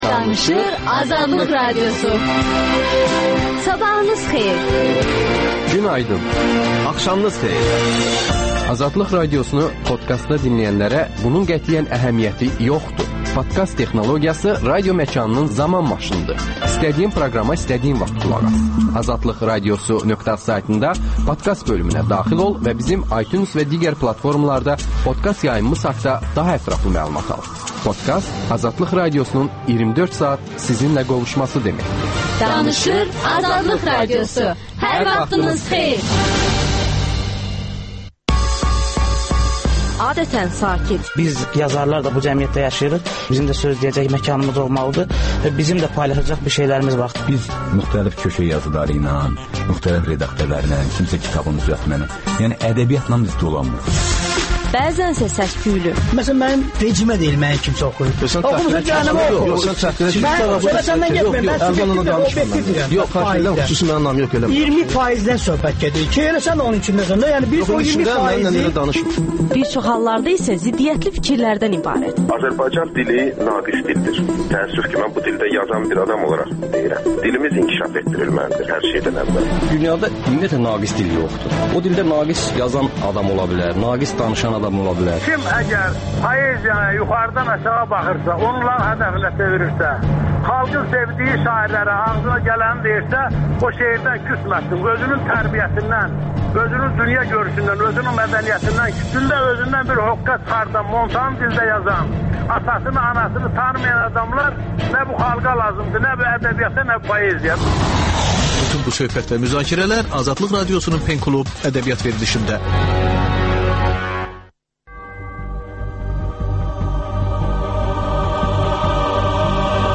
«Qaynar xətt» telefonunda dinləyicilərin suallarına hüquqşünaslar cavab verir.